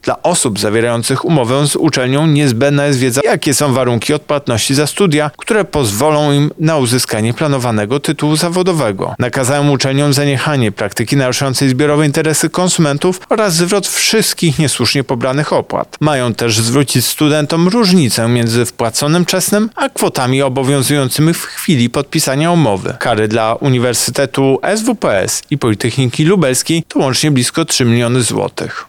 Tomasz Chróstny – mówi Tomasz Chróstny, Prezes Urzędu Ochrony Konkurencji i Konsumentów.
Tomasz-chrostny.mp3